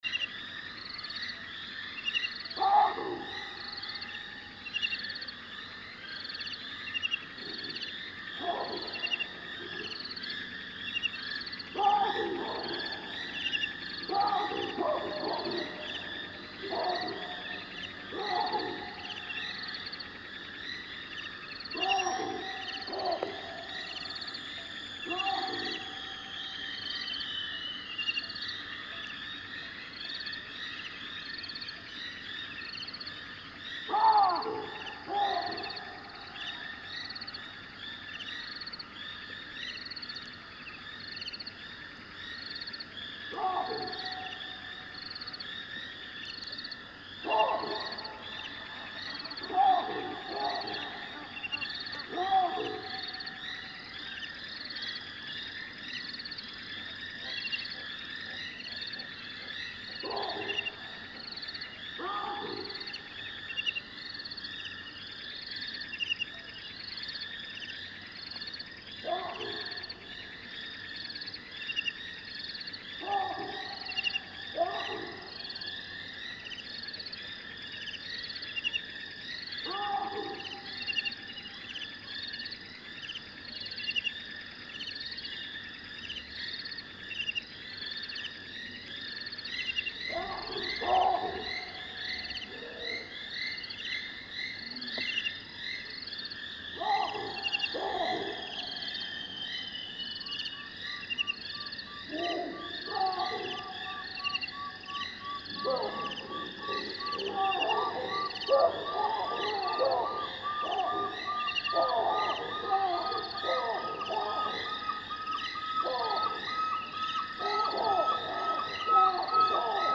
Sound adventures from South Africa – Mmabolela Reserve in Limpopo.
04. Umzumbi with Baboons, Bats
Field Recording Series by Gruenrekorder